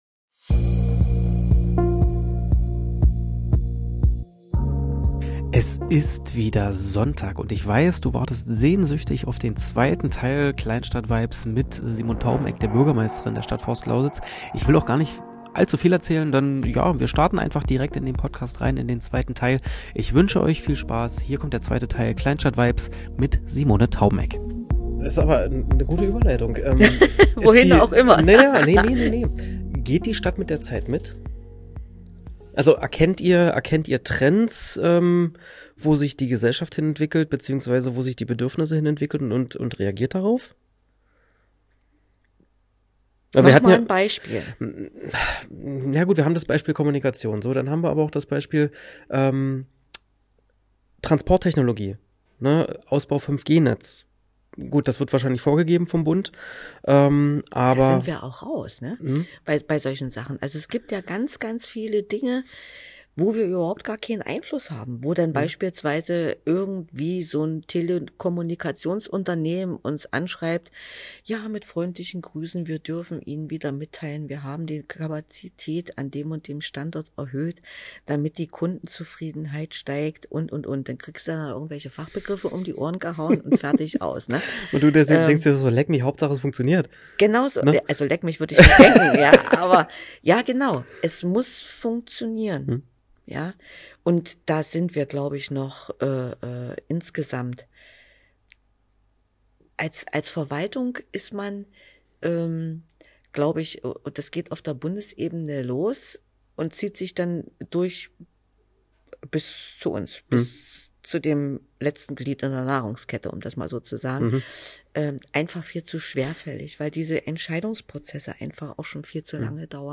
Im zweiten Teil unseres Gespräches mit der Bürgermeisterin, wird es ein wenig Persönlicher. Bei Themen wie das Jugendzentrum, der Skatepark und ähnlichen Projekten, sitzen die Emotionen bei Simone schon sehr tief.